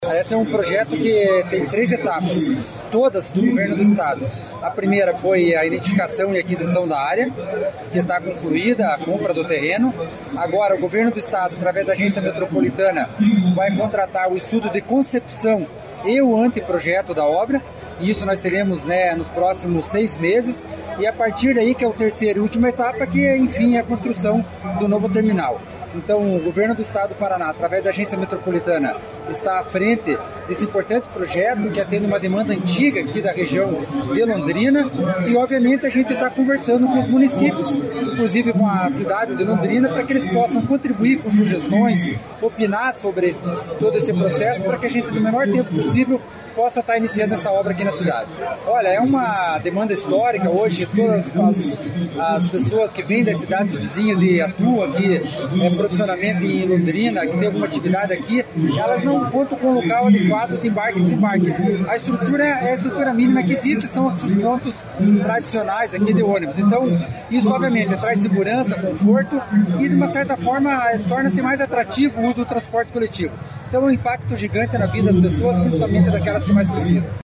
Sonora do presidente da Amep, Gilson Santos, sobre o Terminal Metropolitano de Londrina